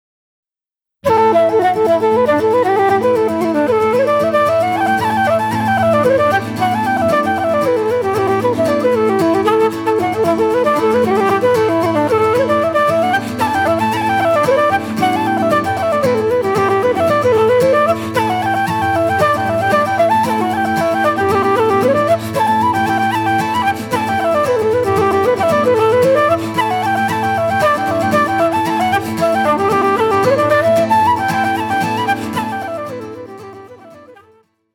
Flutes D, Eb, C, Bb - Low whistles F, D
Guitars
Fiddle, Piano & Harmonium
Wire Strung Harp
Double Bass